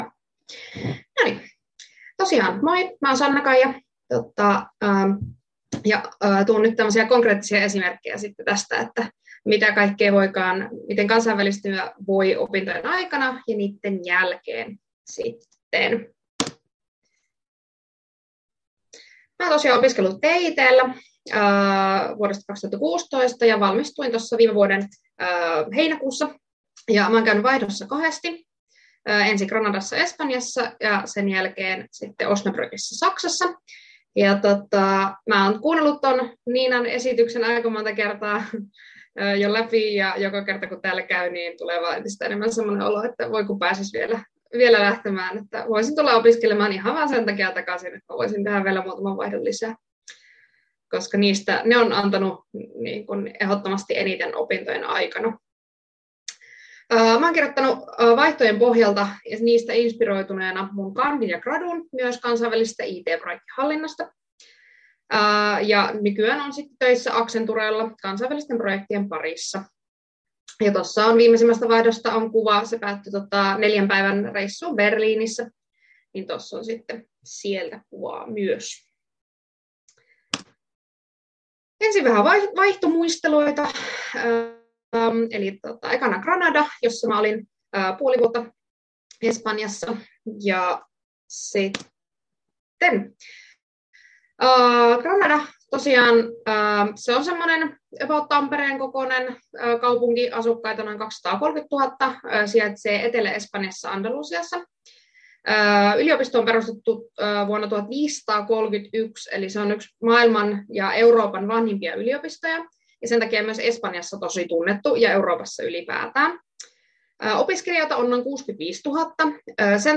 Alumnin puheenvuoro, Kansainvälistyminen ja opiskelijavaihto — Moniviestin